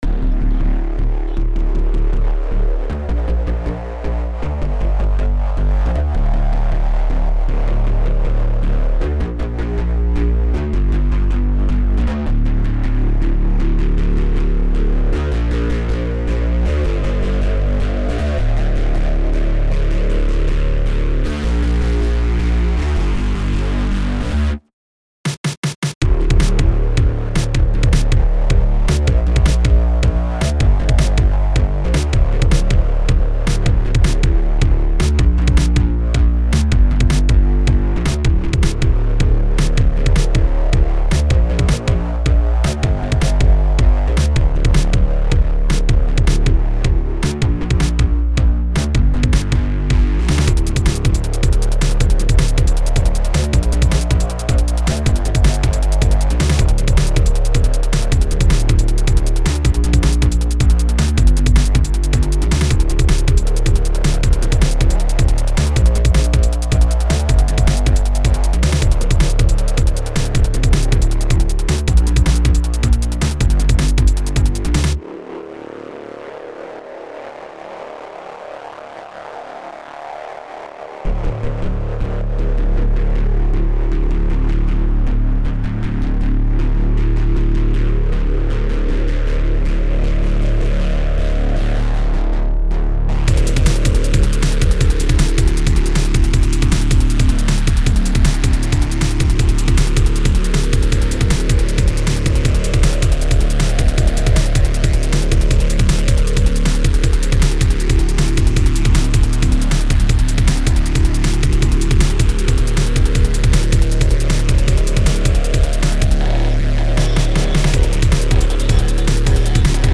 Music / Techno